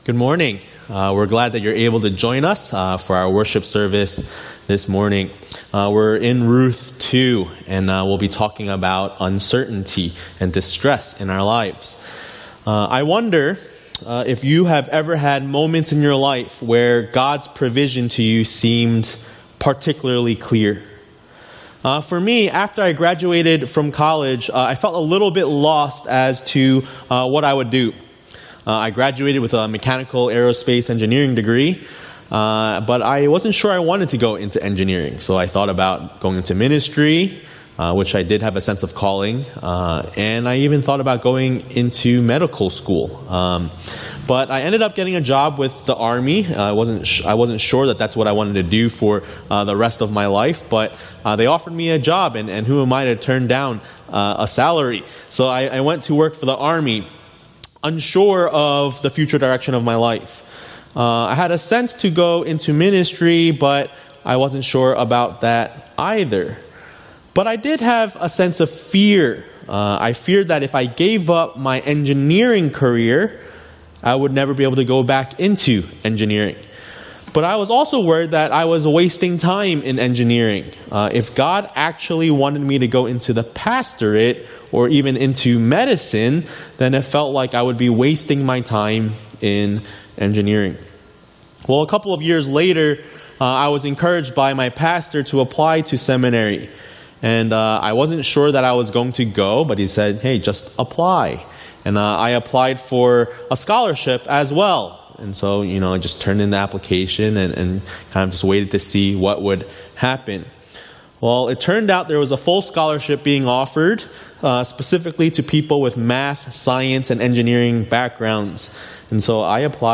A message from the series "Ruth."